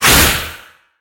assets / minecraft / sounds / mob / wither / shoot.ogg
shoot.ogg